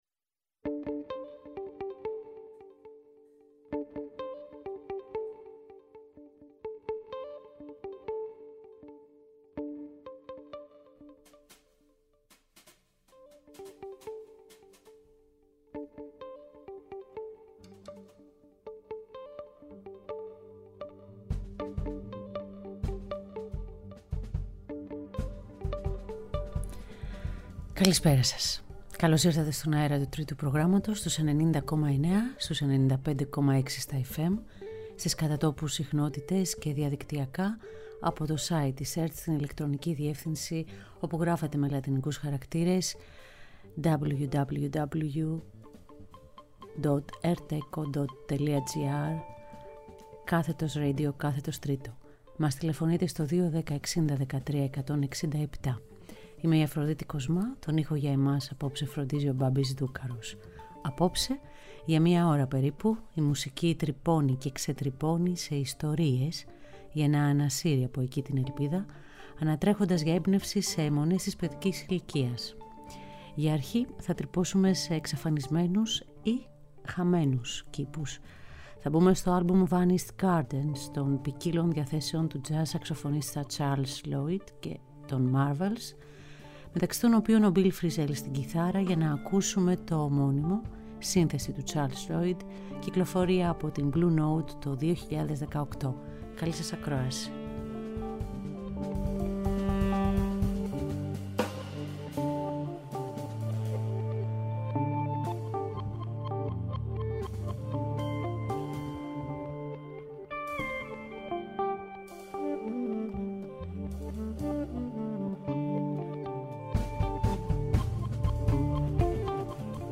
οι χορδές της ηλεκτρικής κιθάρας